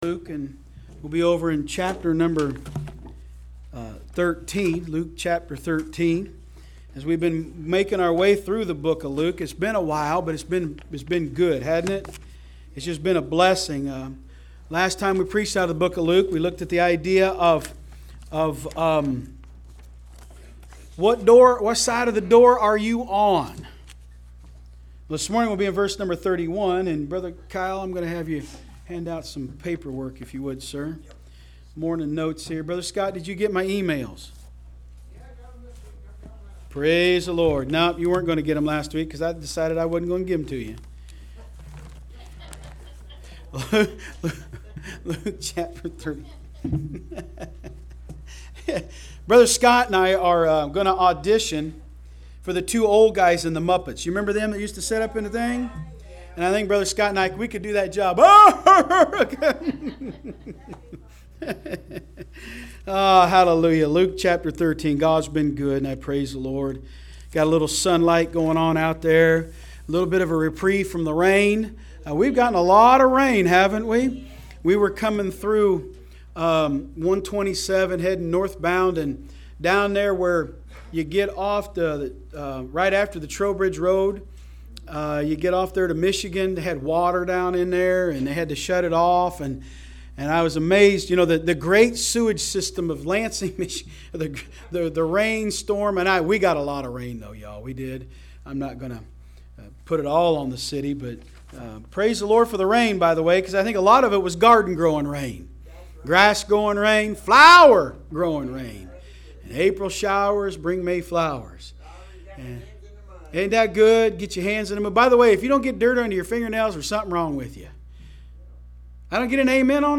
From Series: "AM Service"